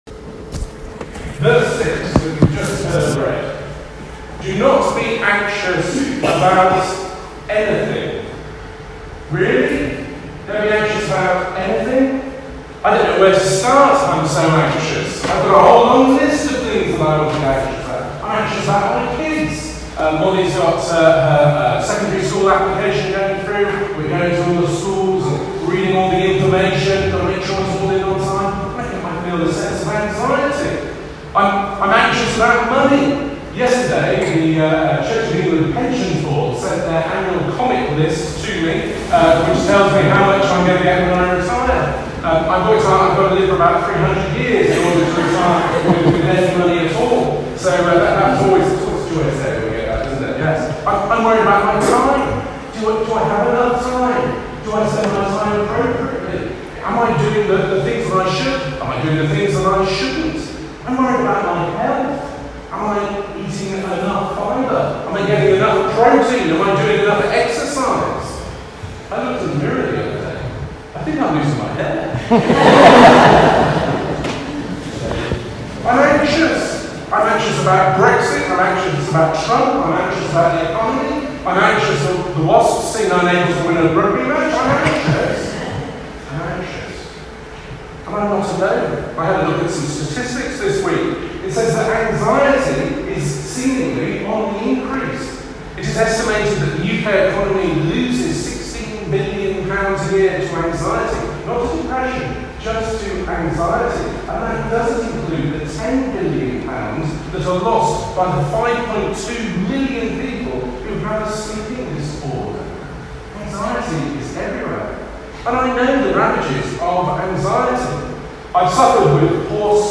On Sunday the 15th October the sermon included the advise to step on your ANTs, you can listen to it here the Bible reading was Philippians chapter 4 verses 1-9